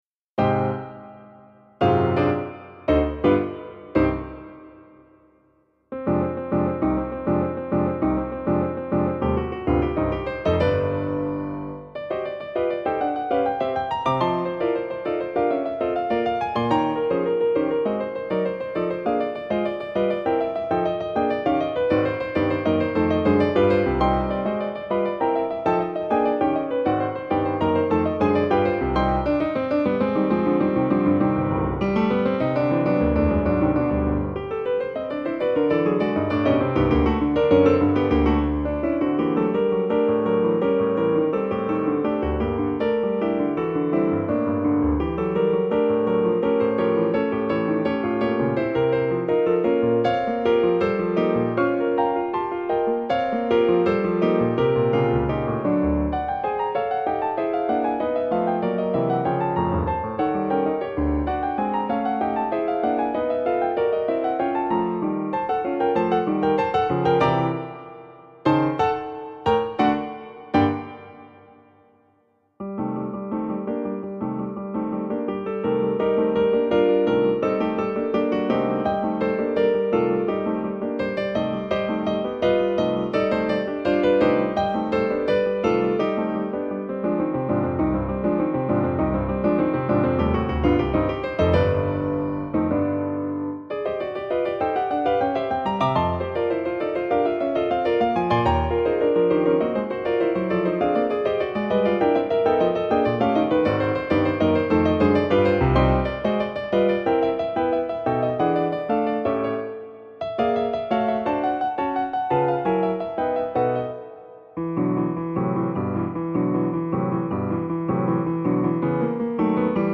クラシックピアノから私のお気に入りをmidiで打ち込んでみました。
mp3 複雑なシンコペーションのリズム、そして計算し尽くされた和声。